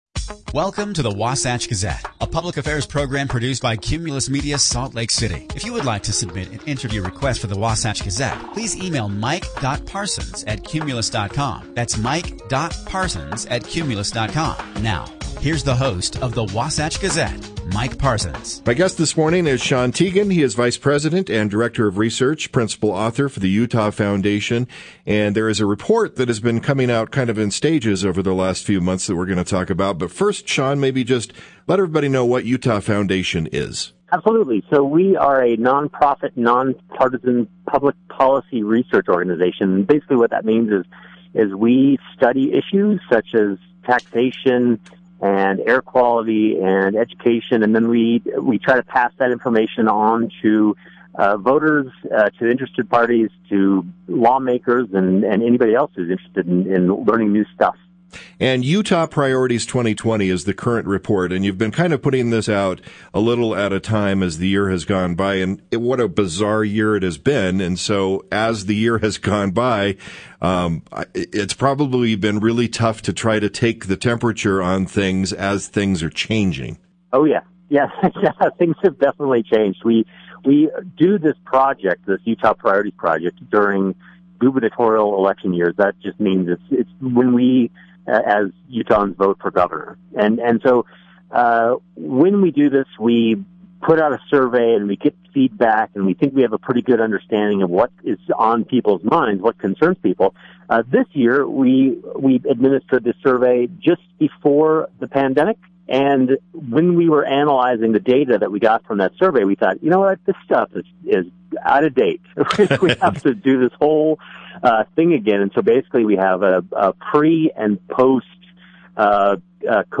The conversation was part of the Wasatch Gazette that plays on Sundays on: